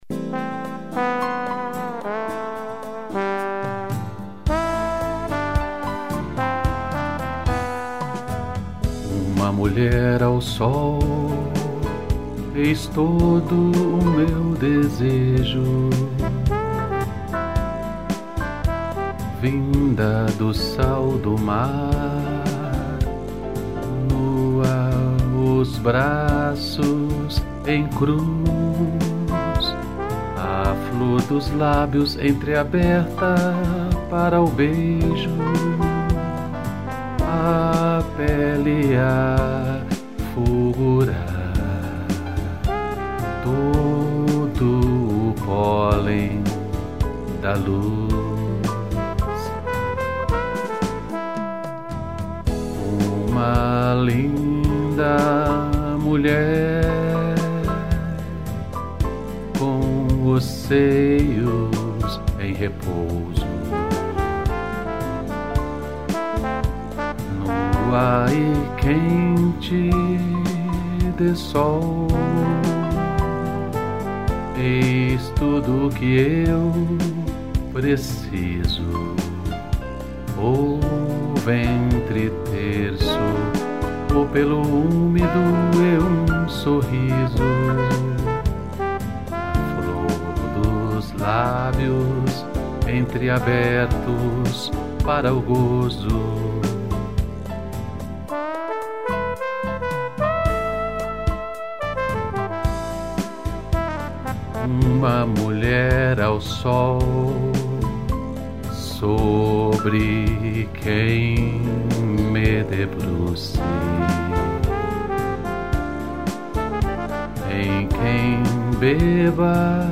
piano e trombone